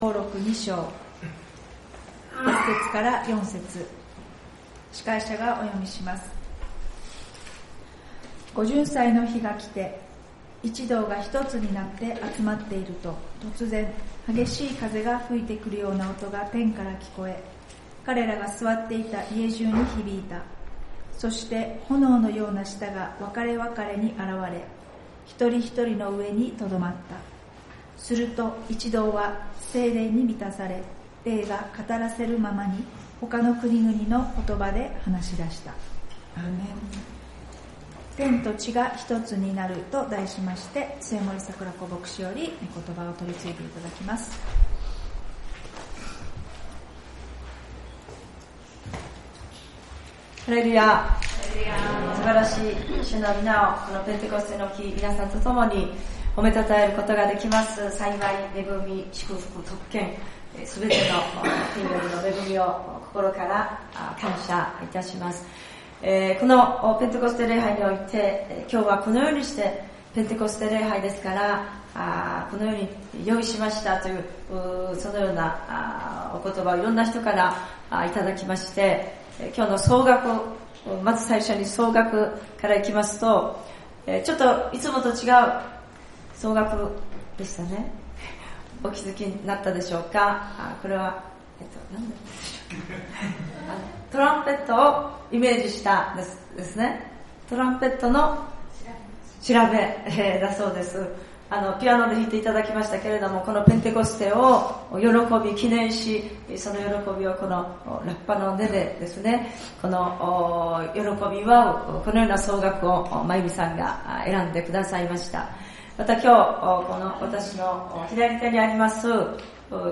ペンテコステ礼拝「天と地が一つとなる」